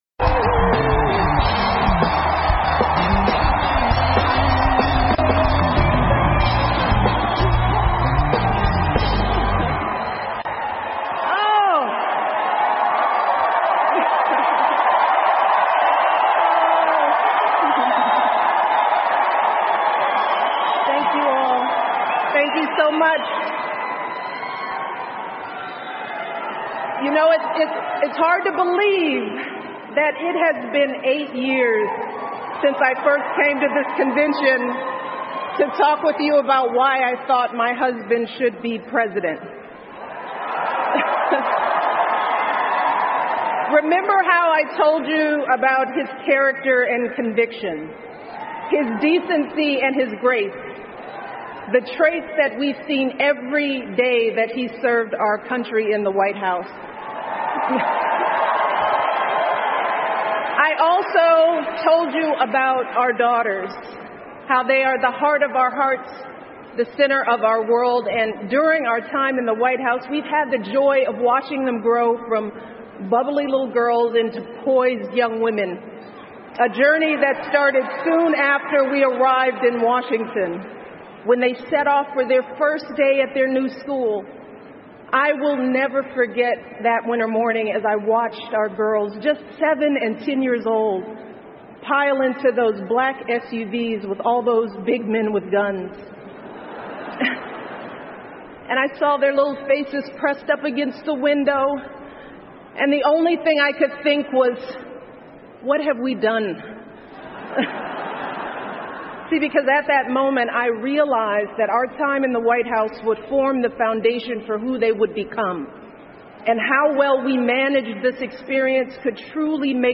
美国总统大选演讲 第一夫人米歇尔·奥巴马为希拉里的助选演讲(1) 听力文件下载—在线英语听力室